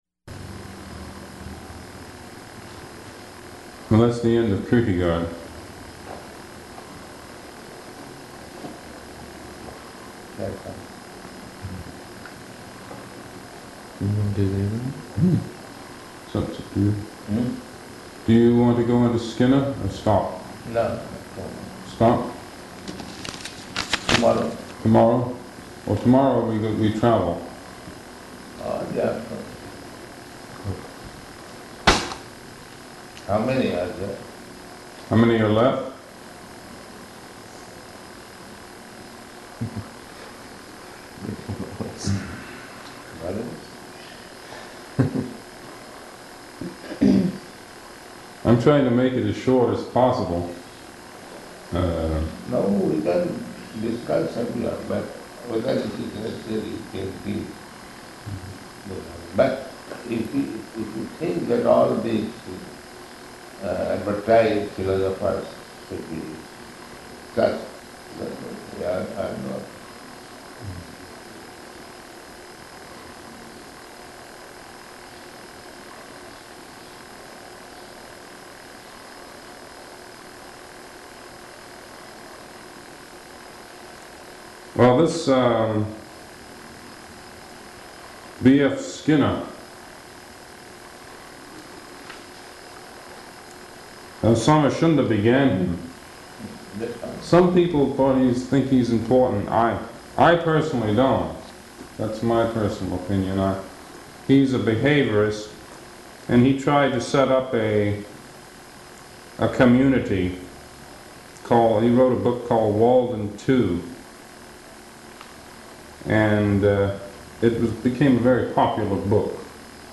Location: Honolulu